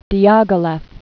(dēgə-lĕf, dyägĭ-lĭf), Sergei Pavlovich 1872-1929.